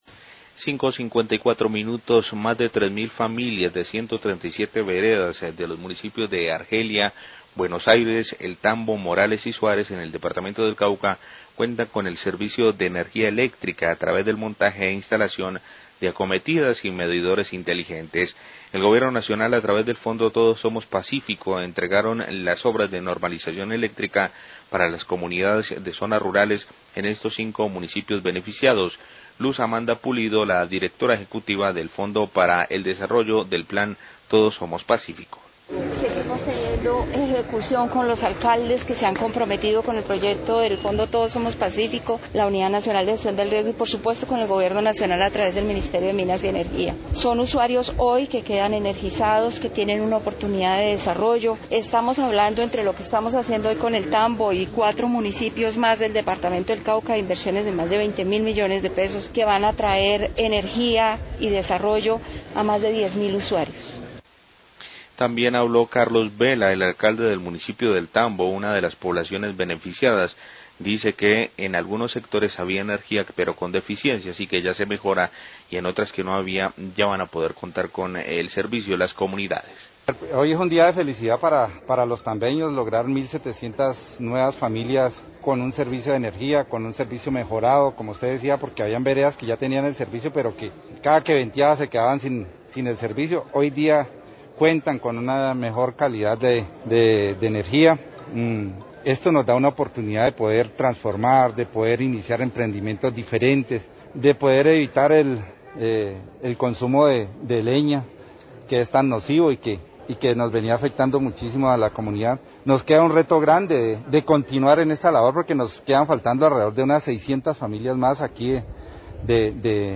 Más de 3 mil familias rurales de 5 municipios del Cauca cuentan ya con el servicio de energía tras la entrega del proyecto de normalización eléctrica. Declaraciones de la directora del Plan Todos Somos Pazcífico, el Alcalde de El Tambo y el Gobernación del Cauca.